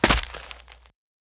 Footsteps
dirt.wav